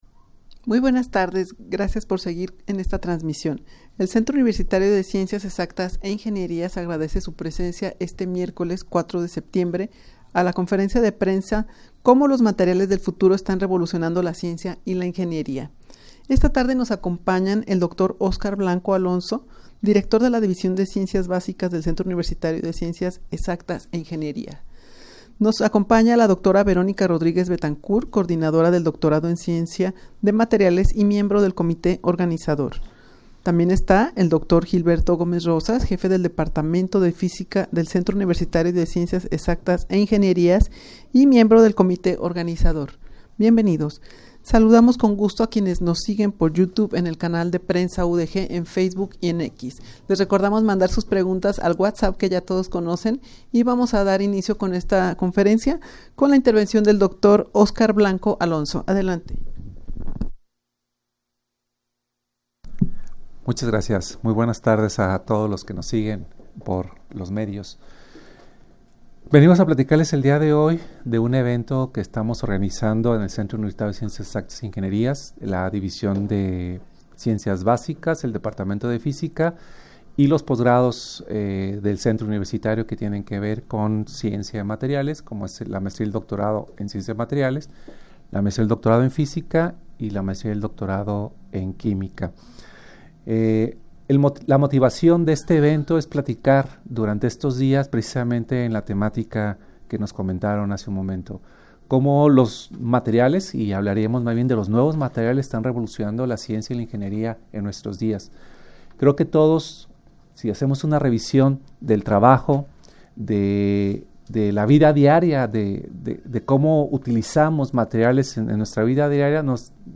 rueda-de-prensa-como-los-materiales-del-futuro-estan-revolucionando-la-ciencia-y-la-ingenieria-.mp3